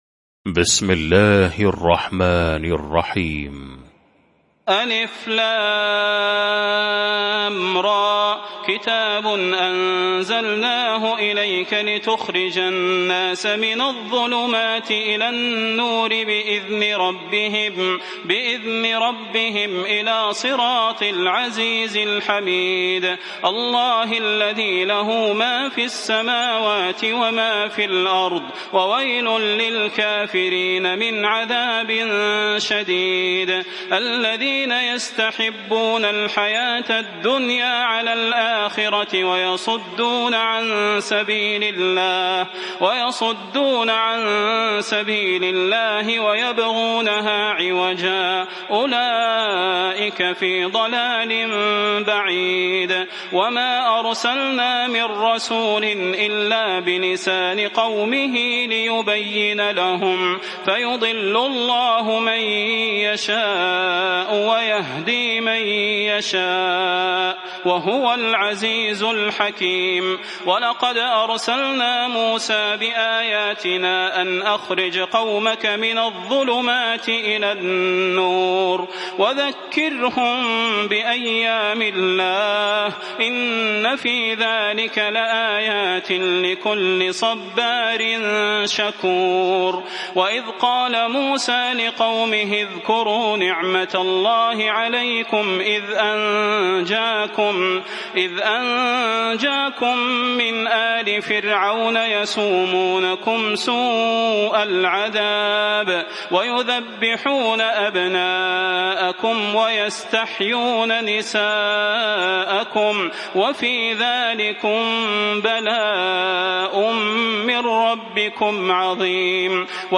المكان: المسجد النبوي الشيخ: فضيلة الشيخ د. صلاح بن محمد البدير فضيلة الشيخ د. صلاح بن محمد البدير إبراهيم